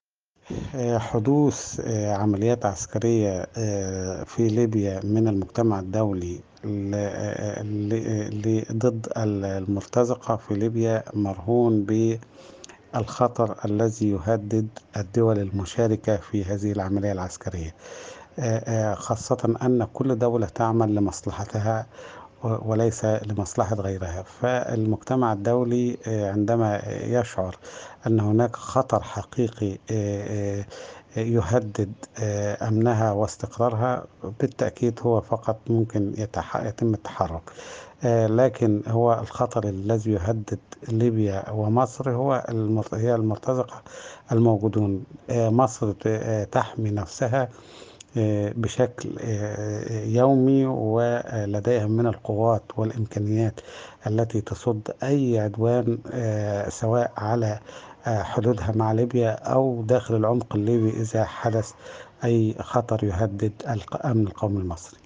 محلل سياسي وكاتب صحفي